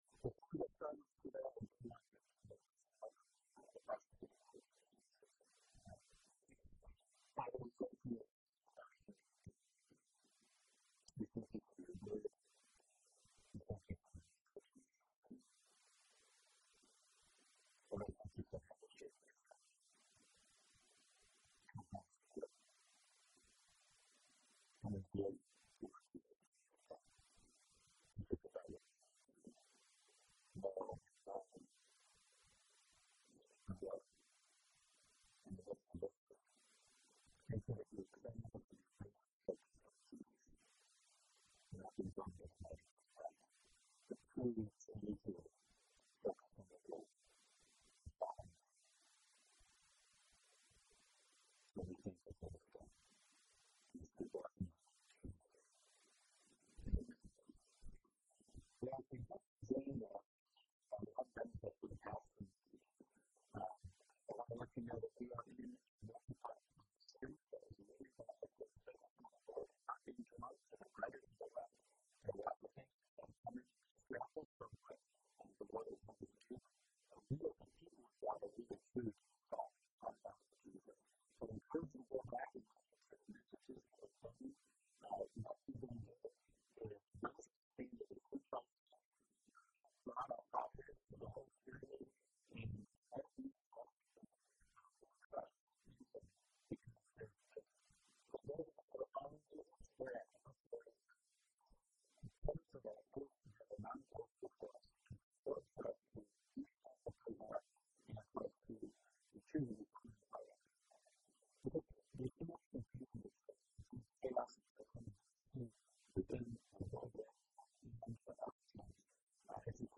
preaching from 2 Corinthians 4:16-18